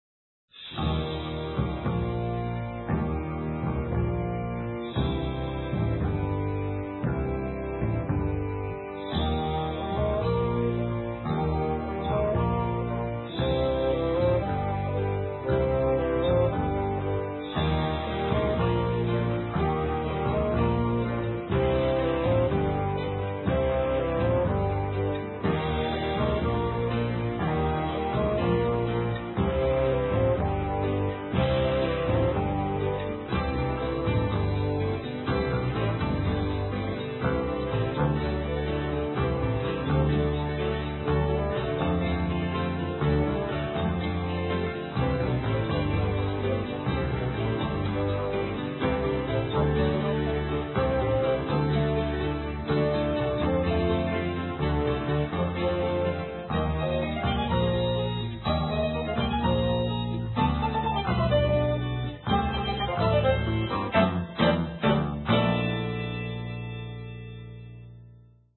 Mono, 1:08, 16 Khz, (file size: 135 Kb).